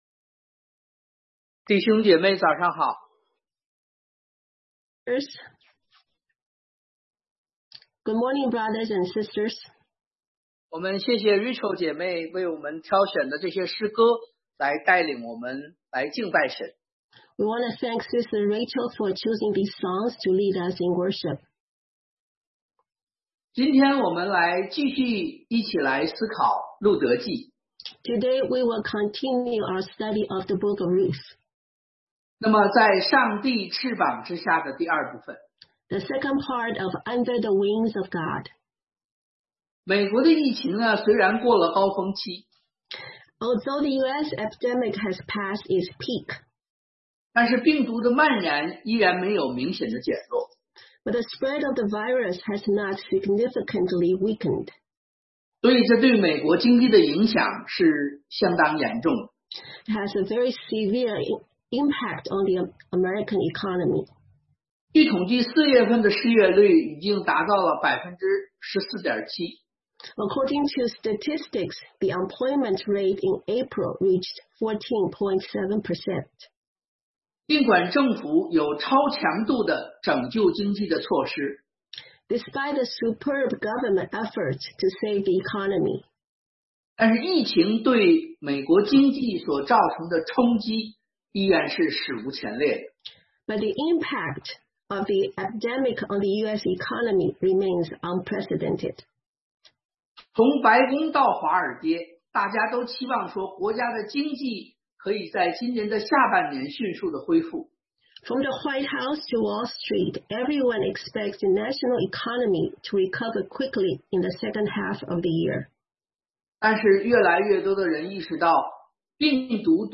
Ruth 2:17-23 Service Type: Sunday AM Under the Wings of God